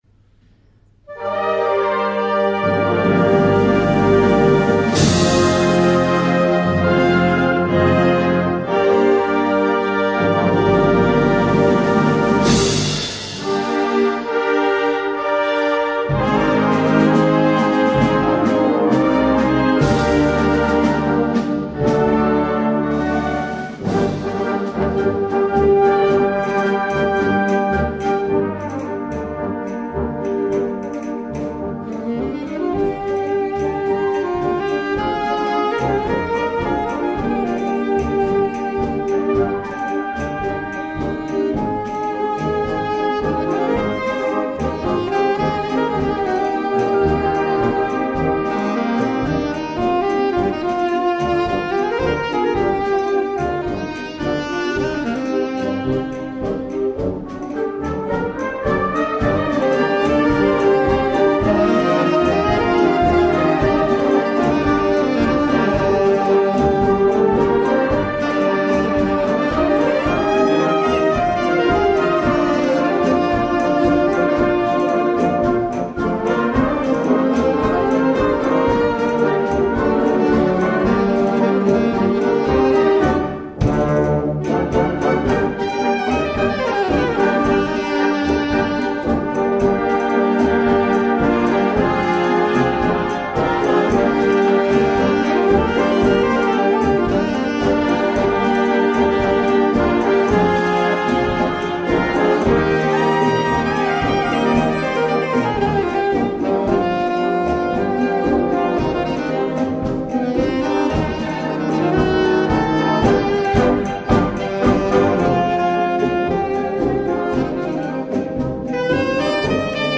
Gattung: Solo für Altsaxophon und Blasorchester
Besetzung: Blasorchester